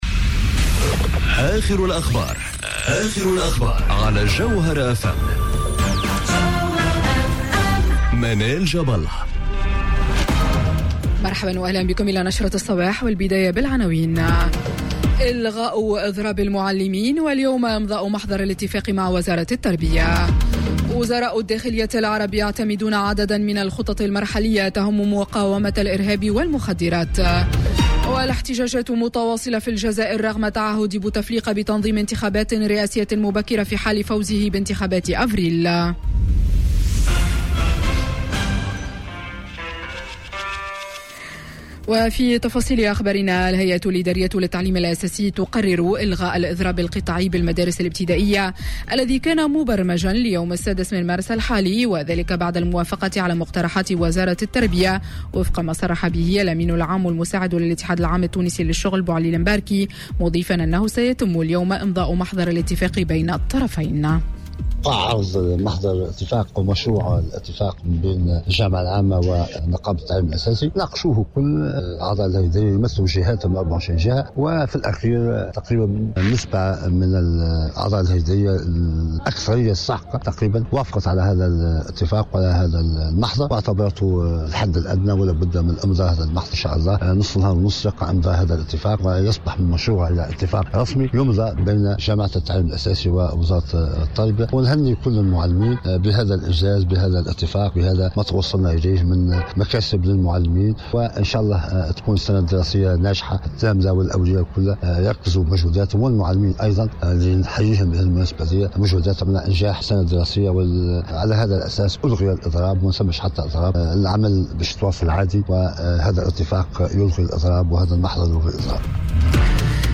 نشرة أخبار السابعة صباحا ليوم الإثنين 04 مارس 2019